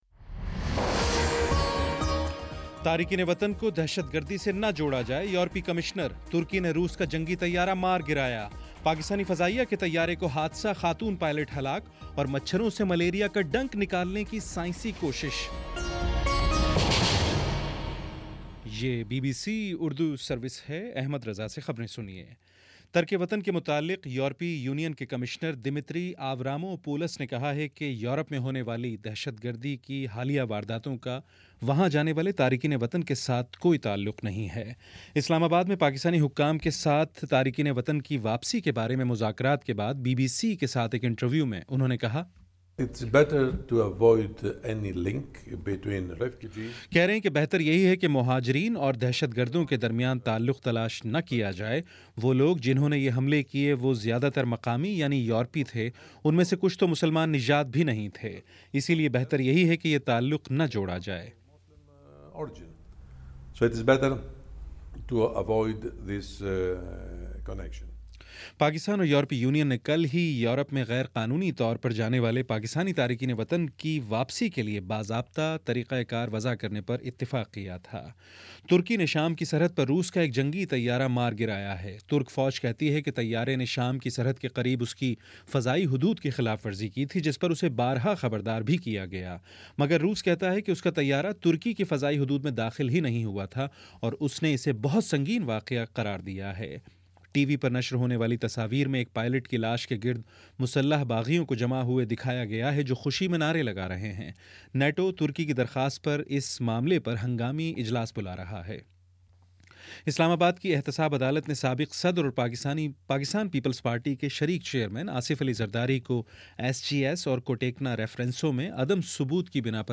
نومبر 24 : شام چھ بجے کا نیوز بُلیٹن